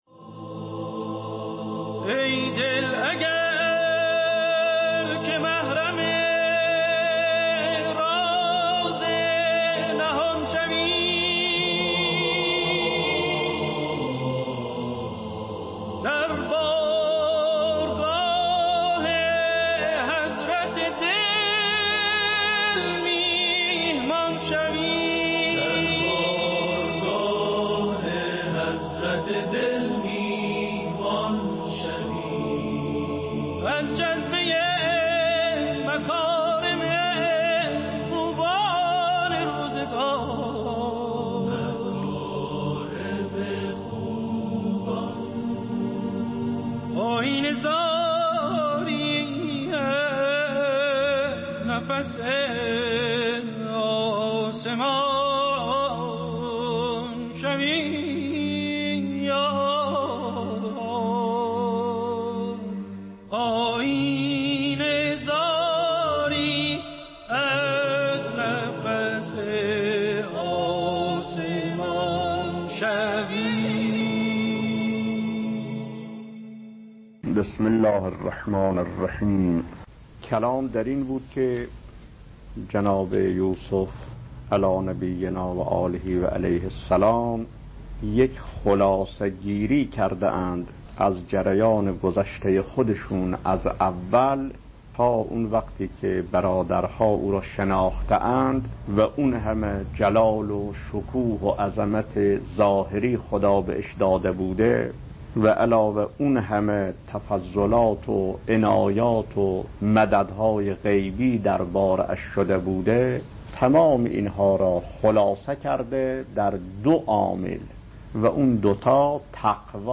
درس اخلاق | چرا خداوند روزه را به عنوان مدرسه تقوا معرفی کرد؟